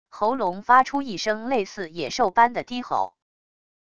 喉咙发出一声类似野兽般的低吼wav音频